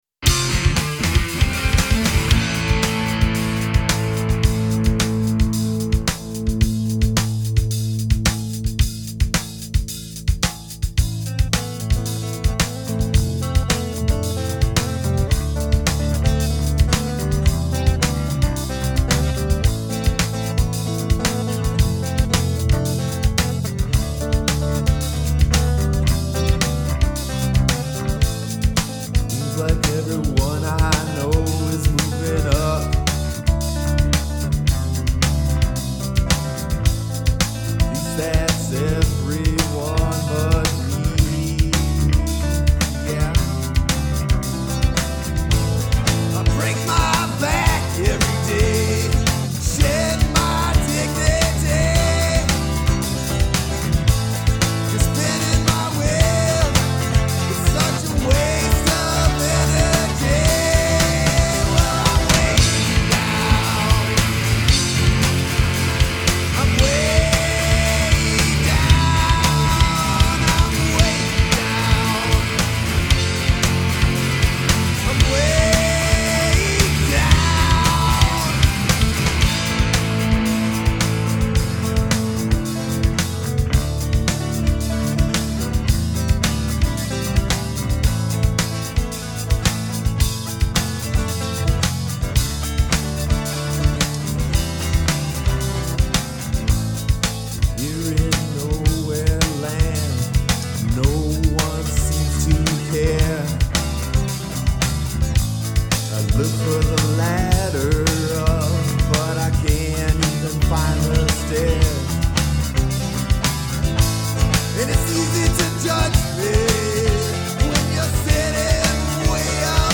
Vocals & Guitars ?
Drums ? - Bass